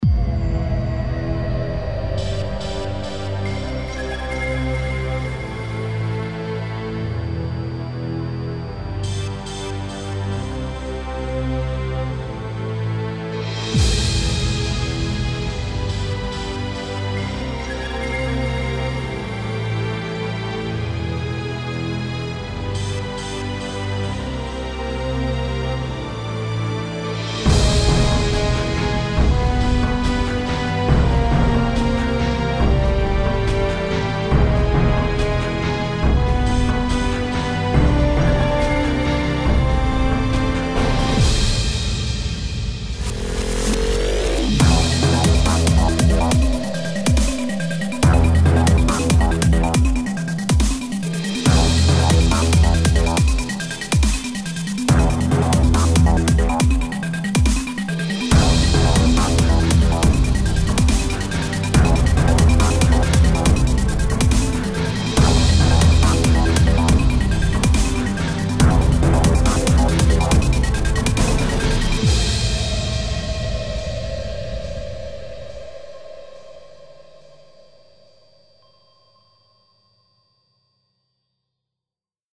来自另一个世界的下一代科幻声音和音轨。
此免版税的声音包总共包含15条音轨，这些音轨也分为各自的部分（鼓，旋律，fx，打击，合唱等等）。